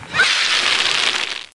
More Applause Sound Effect
Download a high-quality more applause sound effect.
more-applause.mp3